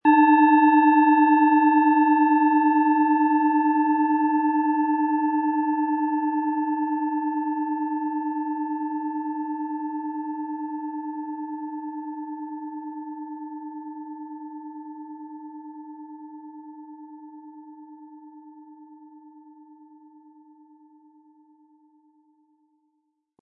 Im Lieferumfang enthalten ist ein Schlegel, der die Schale wohlklingend und harmonisch zum Klingen und Schwingen bringt.
SchalenformBihar
MaterialBronze